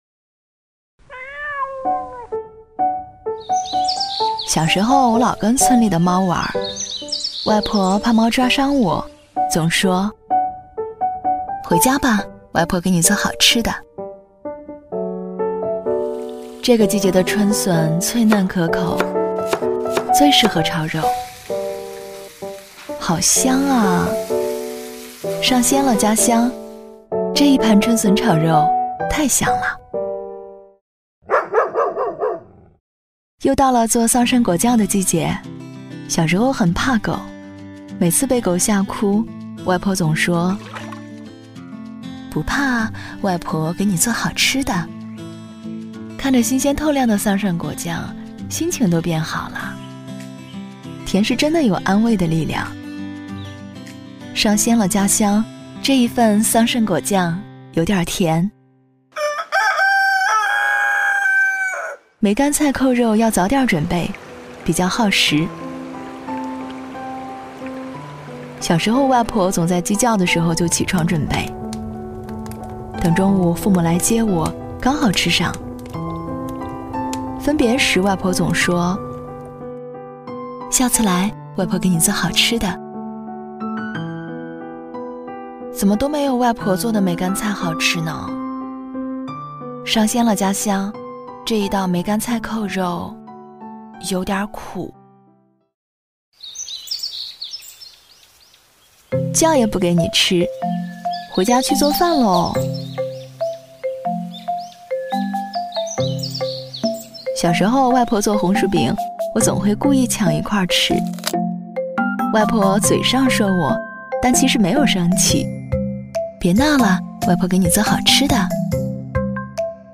国语配音
女267-广告-上鲜了--家乡.mp3